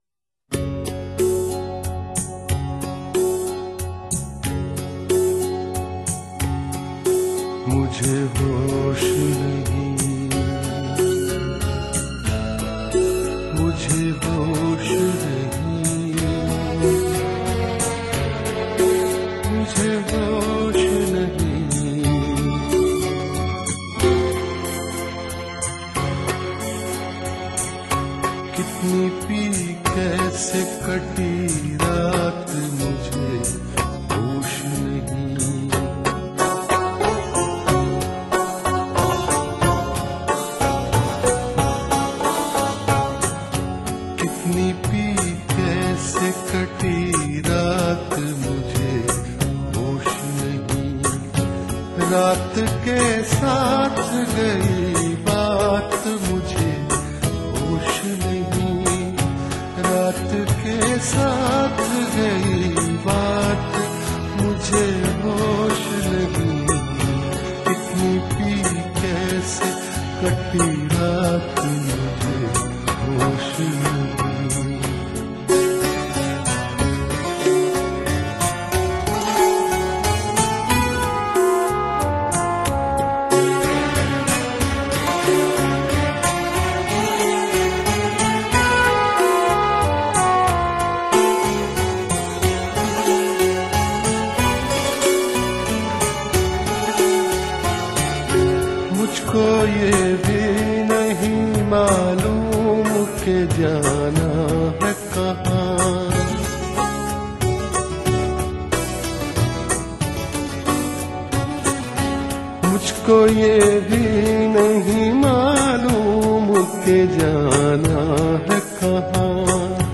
Ghazals